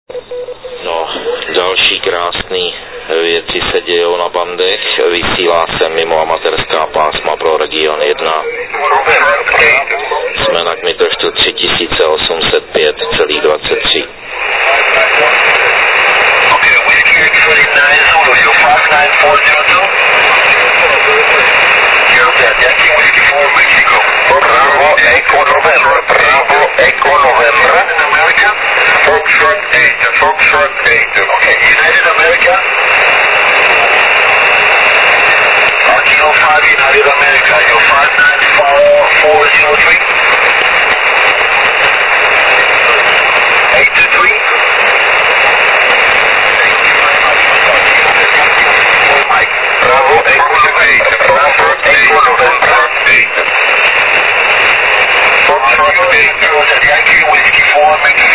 Všechny nahrávky pocházejí z FT817.